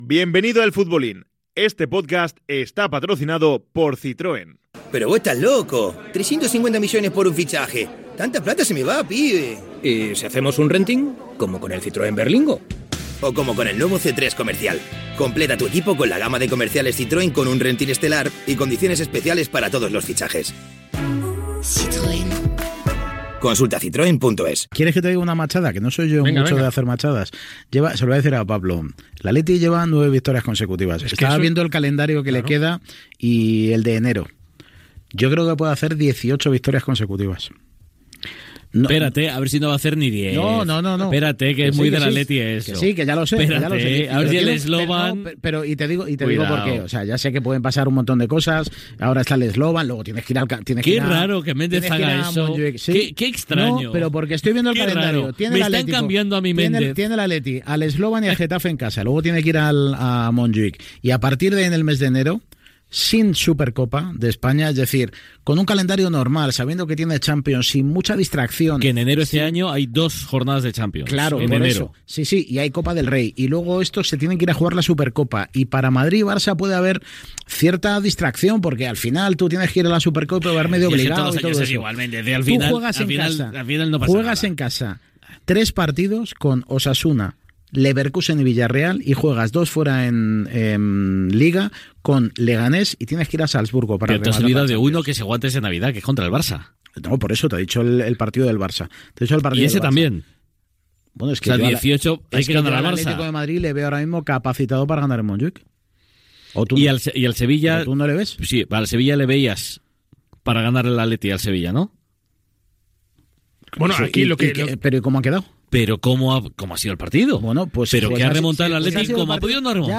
Indicatiu del programa, publicitat i comentaris sobre l'actualitat futbolística masculina
Esportiu